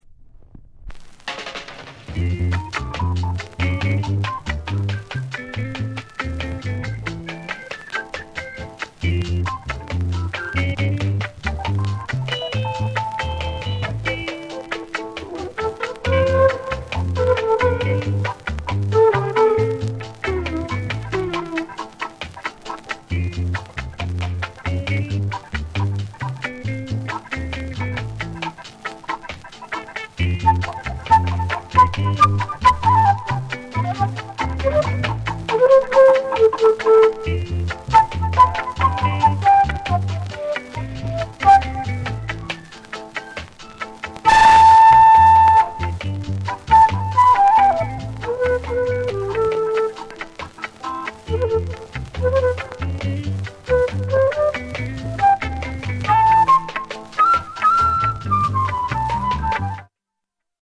NICE INST